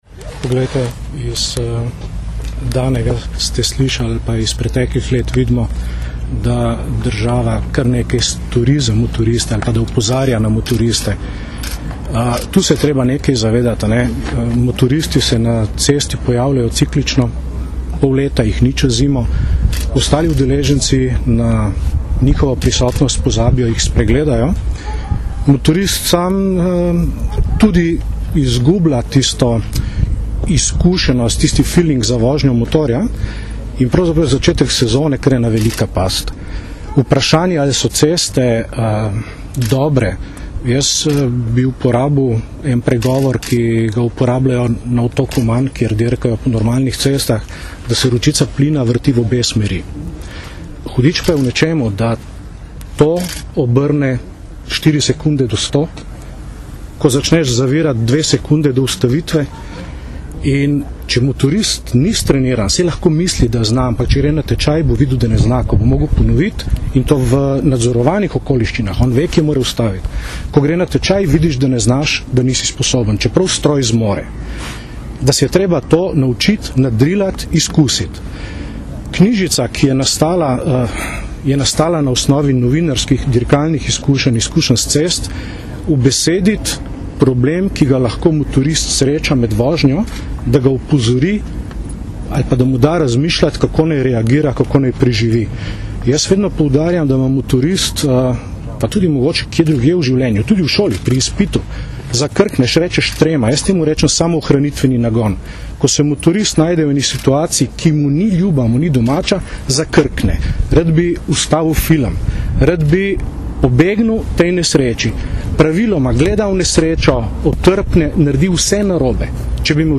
Ob začetku motoristične sezone predstavili akcijo za večjo varnost motoristov - informacija z novinarske konference
izjava (mp3)